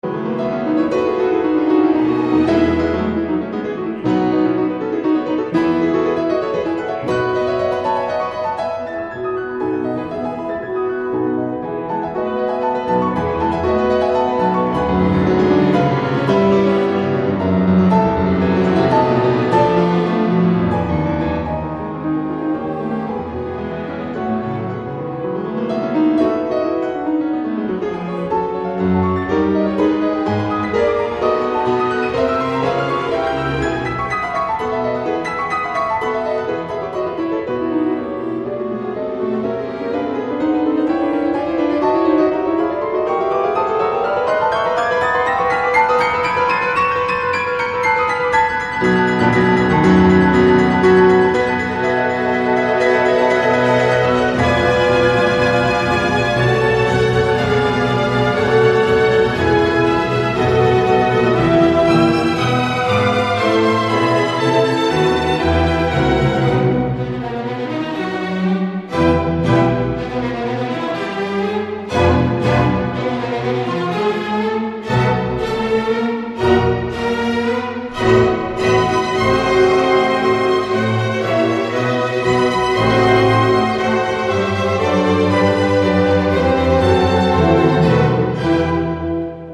古典音樂、發燒天碟